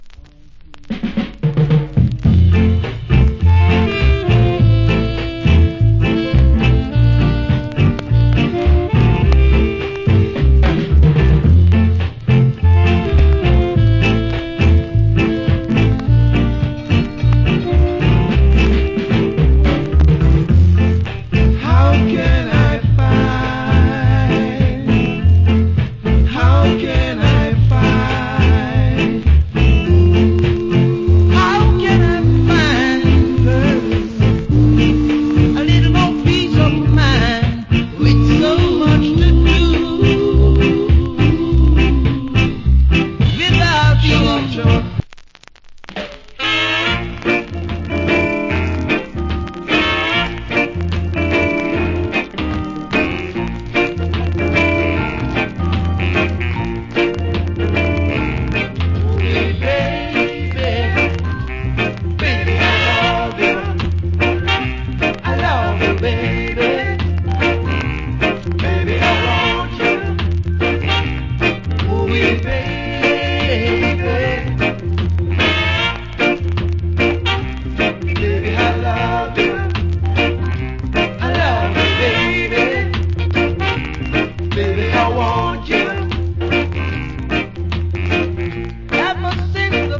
TOP >ROCK STEADY
Cool Rock Steady Vocal.